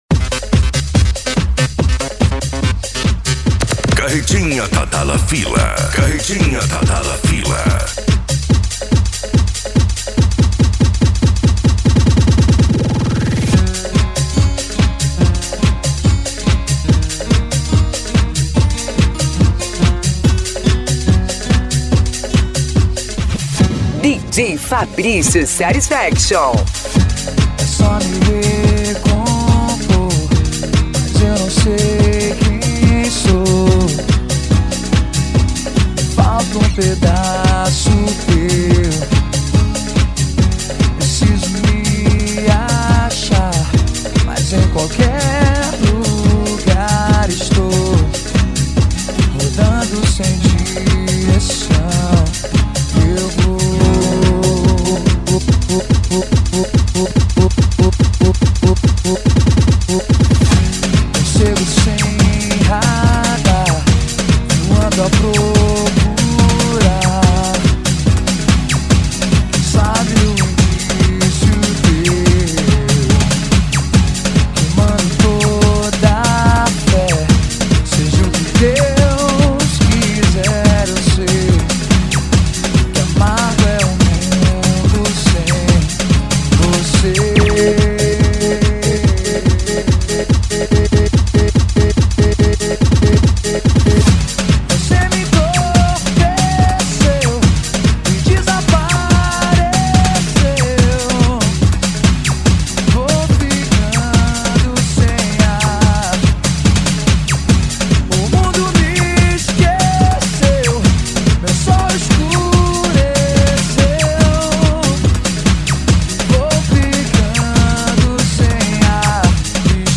PANCADÃO
Retro Music
SERTANEJO